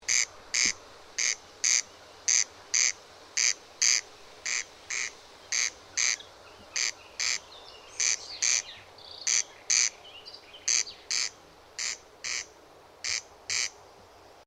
Wachtelkönig
Der unverkennbare Gesang des Wachtelkönigs ist bei guten Bedingungen sehr weit zu hören und wird oft stundenlang wiederholt.
Wachtelkoenig.mp3